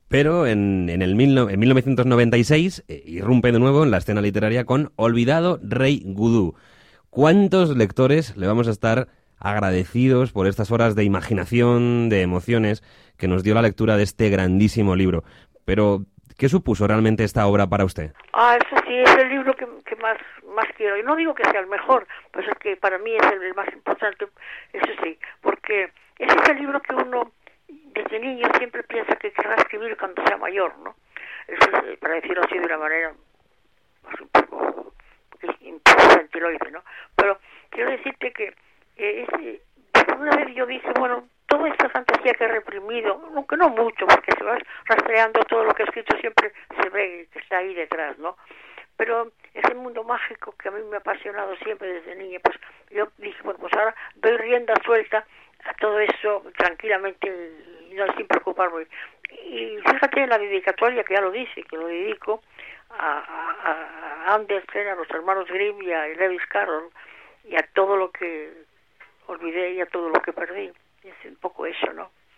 Entrevista a Ana María Matute: un regalo para el día del libro